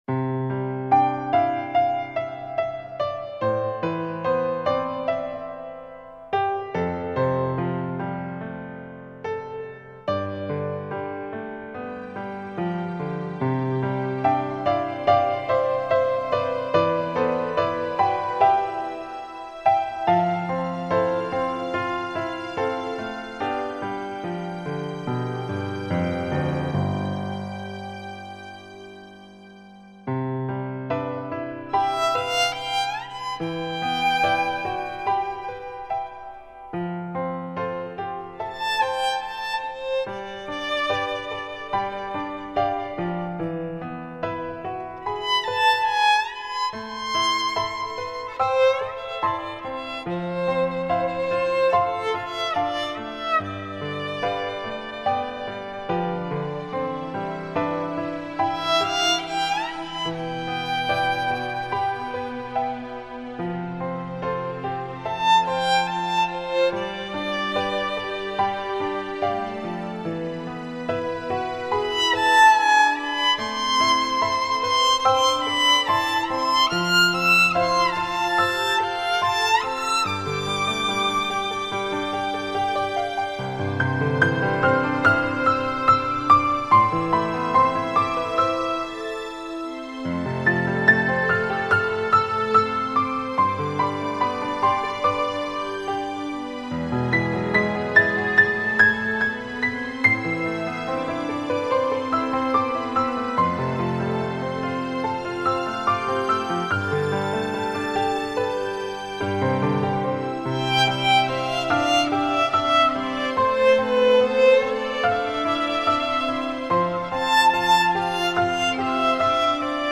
浪漫新世纪音乐
听，黑管与长笛缠绕缱绻如丝的爱意。
听，大提琴与小提琴合鸣凝神的倾听。
听，键盘上黑色精灵与白色天使，悠然舞动藏在夜风里的秘密心曲。